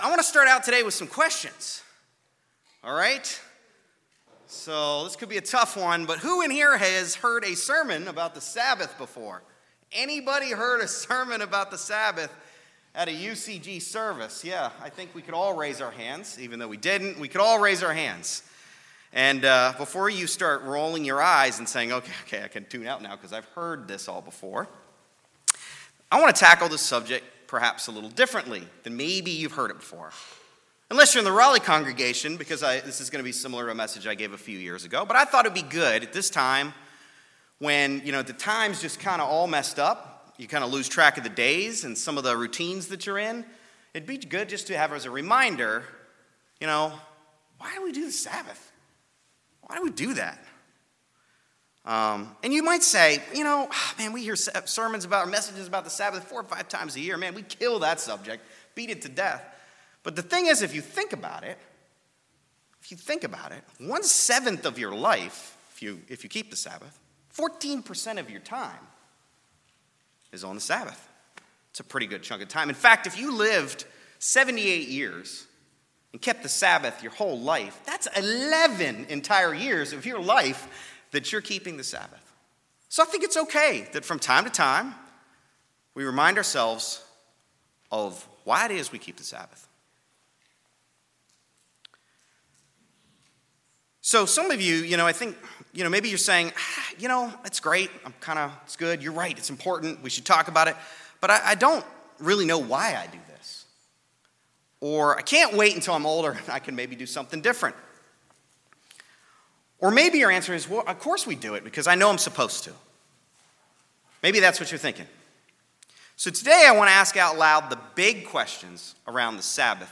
Sermons
Given in Greensboro, NC Raleigh, NC